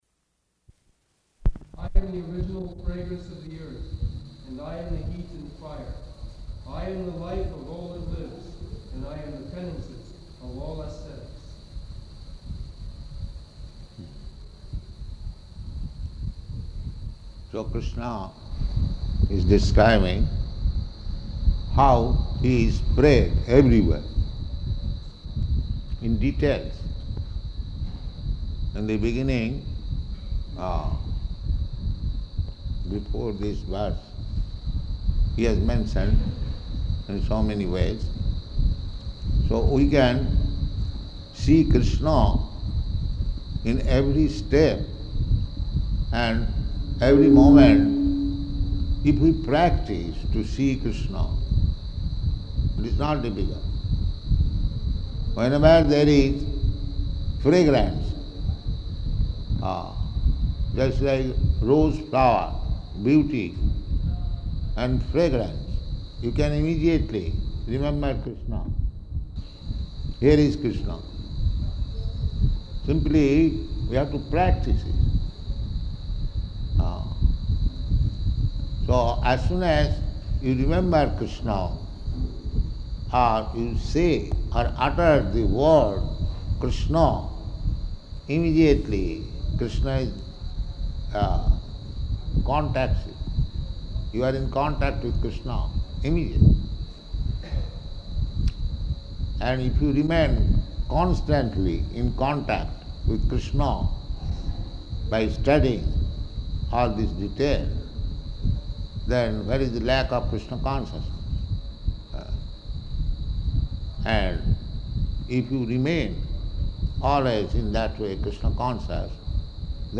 August 15th 1974 Location: Vṛndāvana Audio file